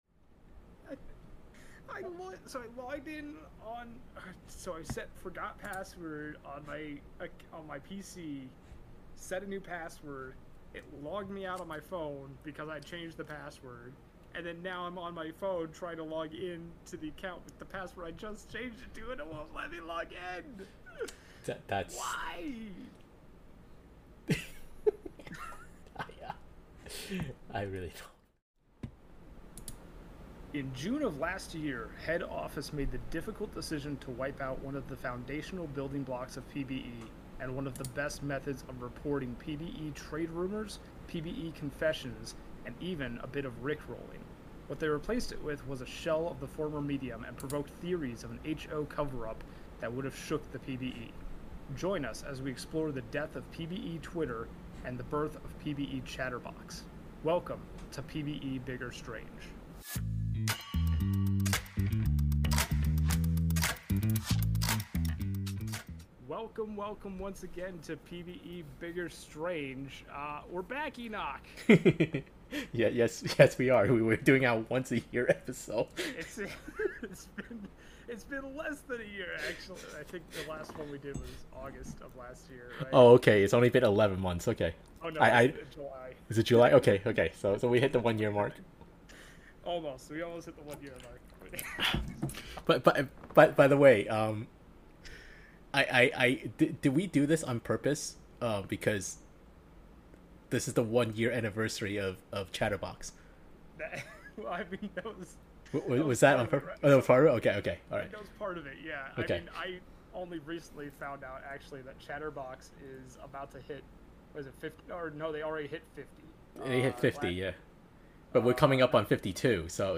Sorry for the poor audio